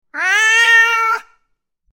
Cat Screaming Sound Effect
Cat-screaming-sound-effect.mp3